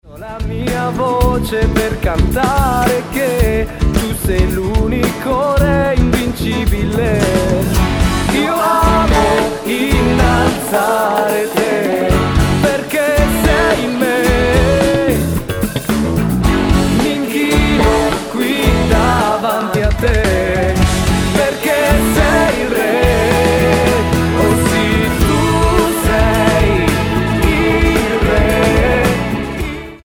cantautore
un Cd live